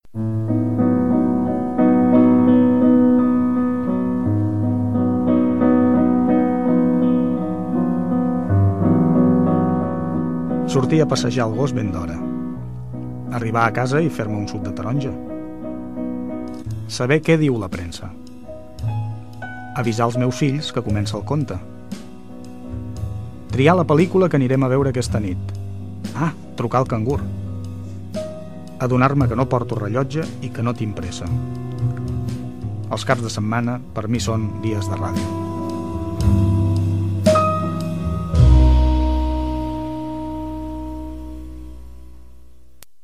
Identificació del programa